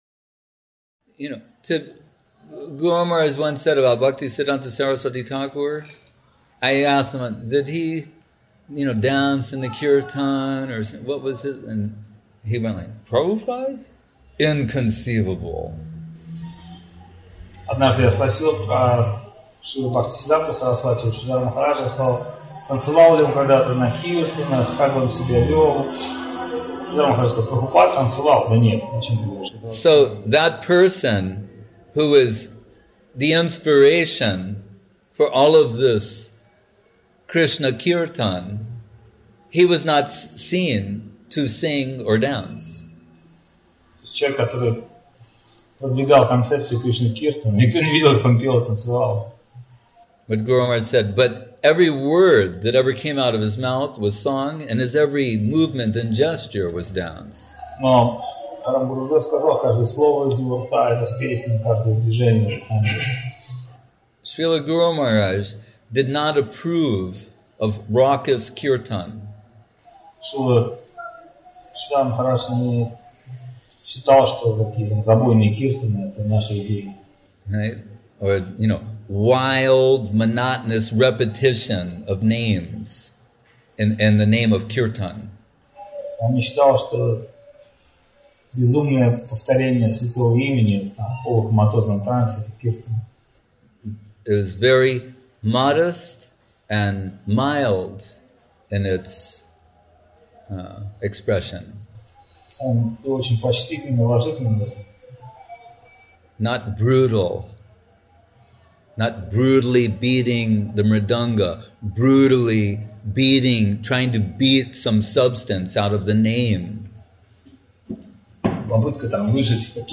Place: SCSMath Nabadwip